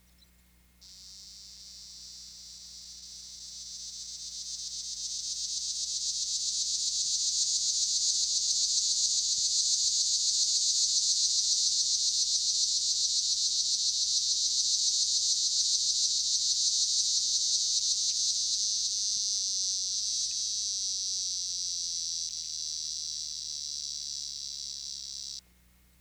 Natural History Museum Sound Archive Species: Tibicen linnei
Microphone & Power Supply: D33A Recorder Recorder: Magnemite 610E Tape Speed (cm/s): 38.00
04_Tibicen linnei.wav